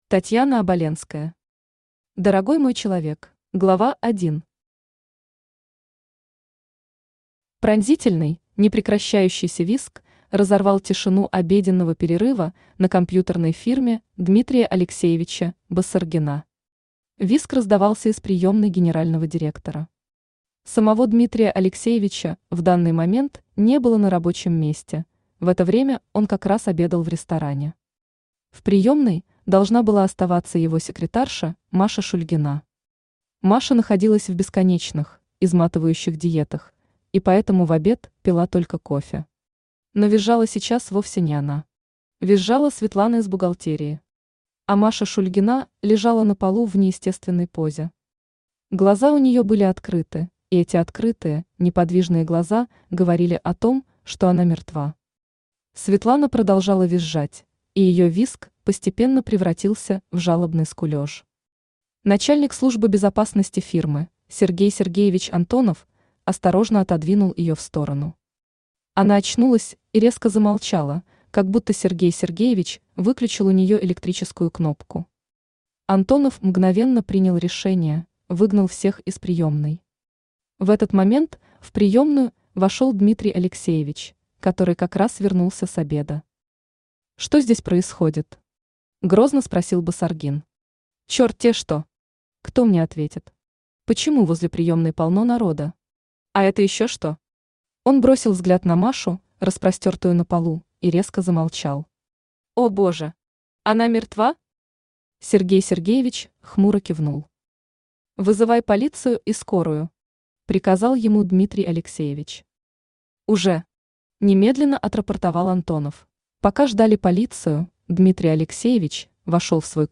Аудиокнига Дорогой мой человек | Библиотека аудиокниг
Aудиокнига Дорогой мой человек Автор Татьяна Оболенская Читает аудиокнигу Авточтец ЛитРес.